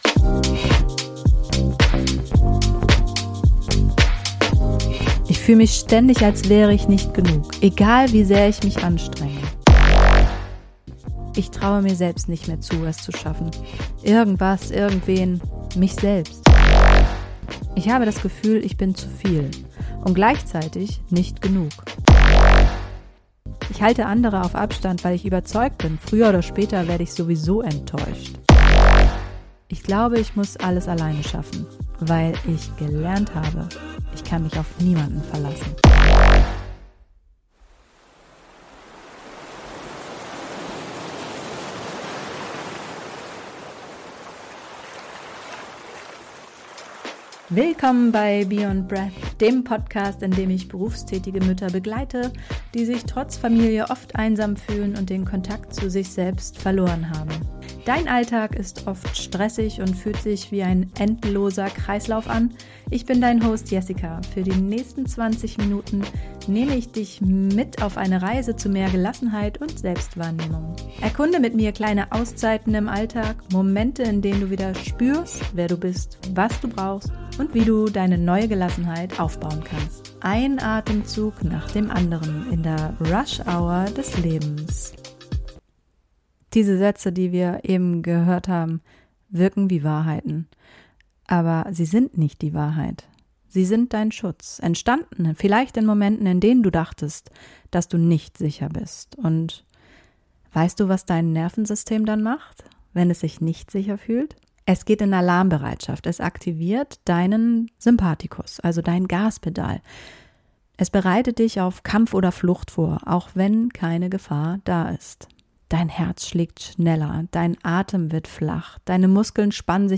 Du erfährst, wie Angst im Körper wirkt und warum dein Atem der erste Hebel zur Besserung ist. Außerdem: eine neue Studie belegt, wie Conscious Connected Breathwork online wirksam gegen Angst hilft. Zum Abschluss leite ich dich durch eine kleine Mini-Atemübung zum Ankommen und Spüren.